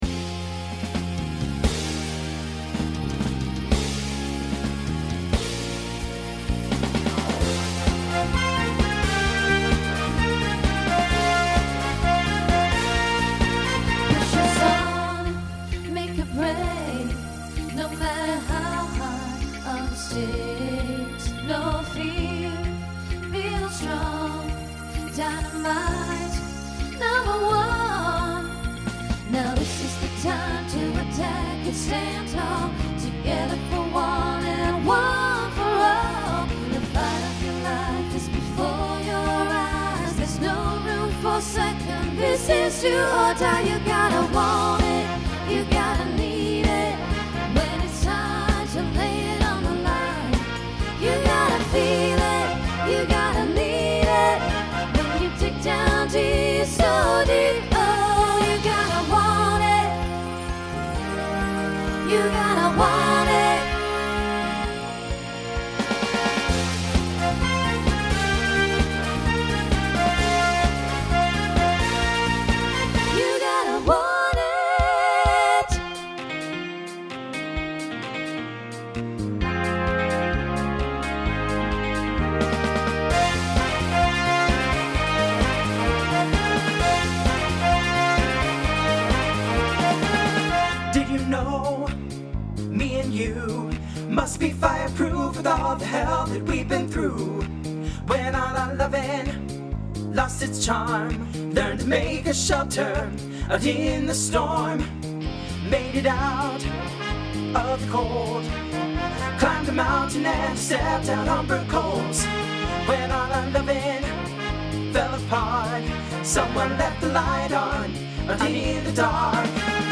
SSA/TTB
Voicing Mixed
Pop/Dance , Rock